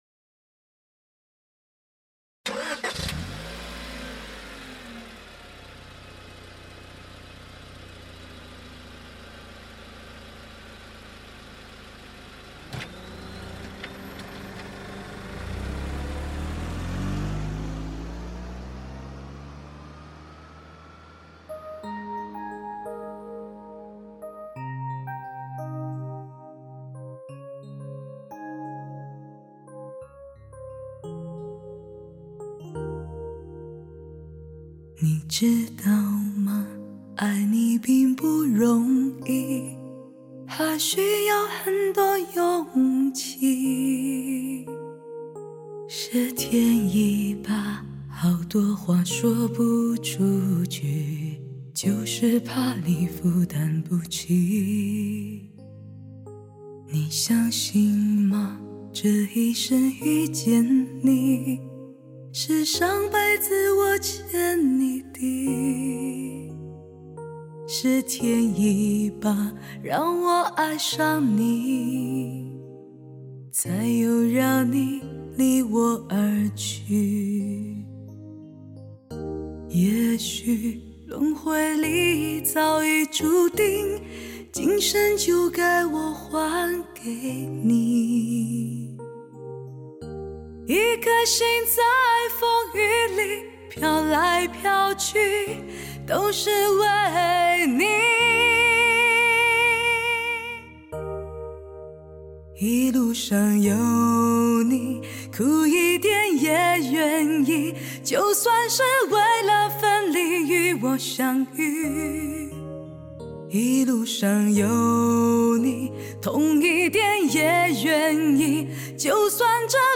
新型DTS-ES 6.1一级编码，创造超乎想象完美环绕